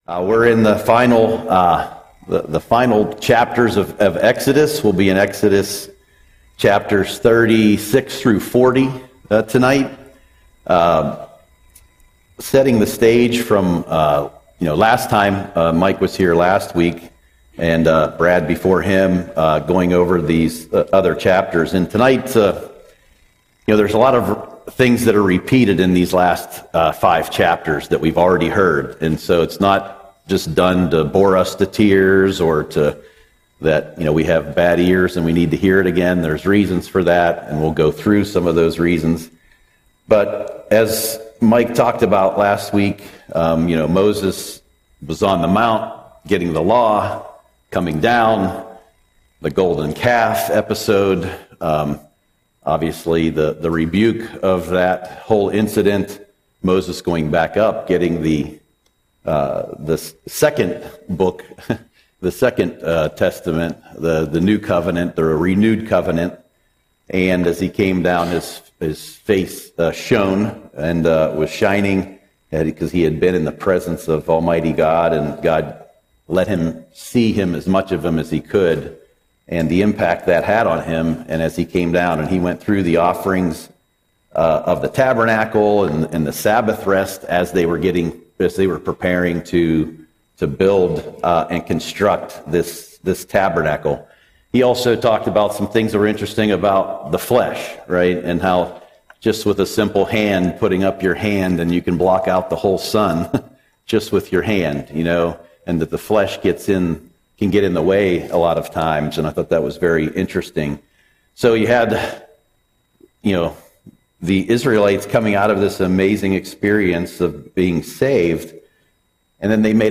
Audio Sermon - July 9, 2025